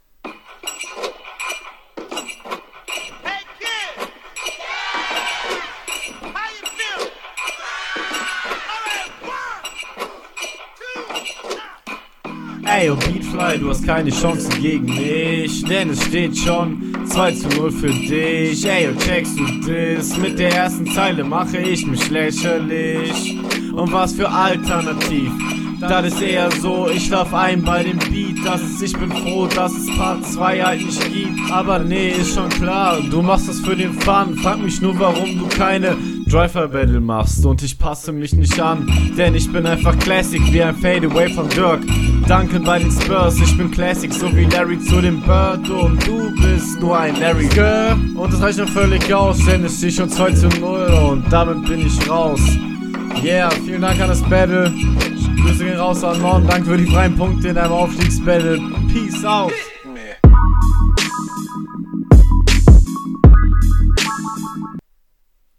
Flow: flowlich kommst du hier leider nicht ganz so on point wie dein Gegner.